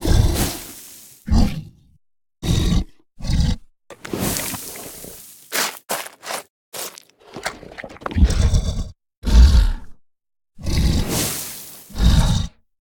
Sfx_creature_snowstalker_chill_01.ogg